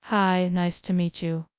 tts.wav